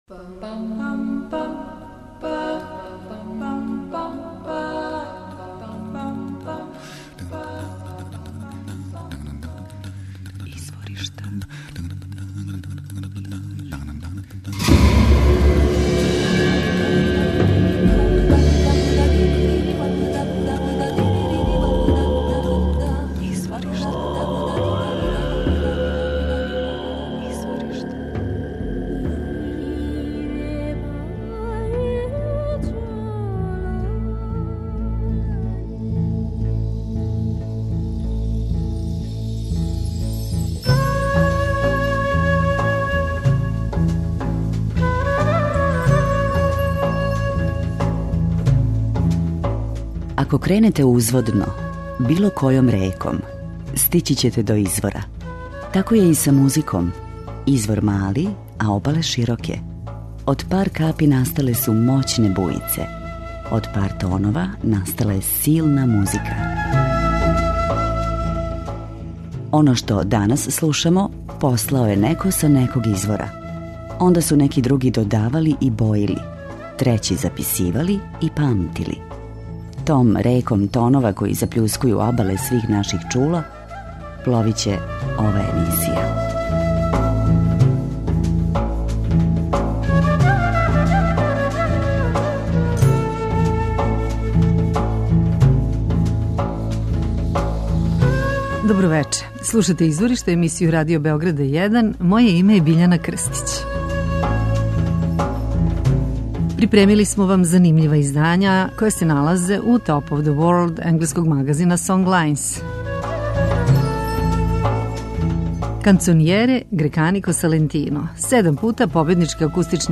виолинисте и перкусионисте